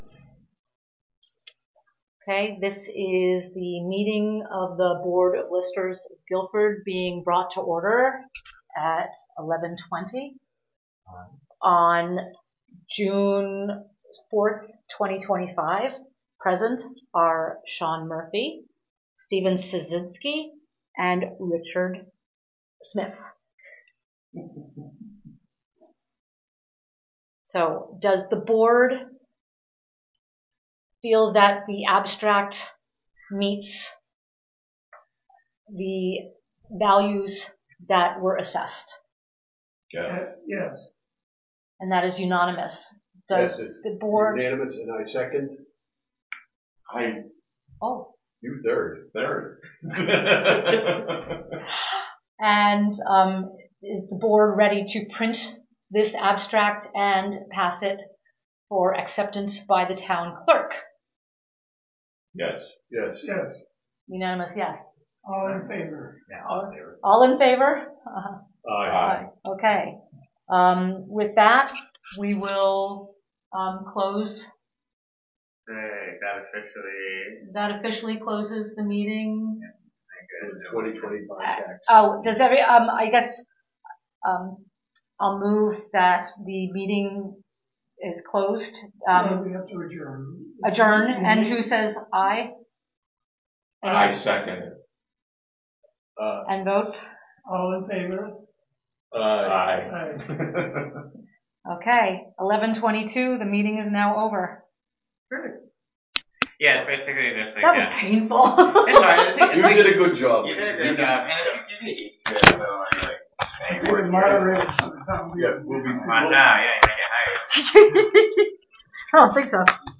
Listers Meeting Minutes 06.04.25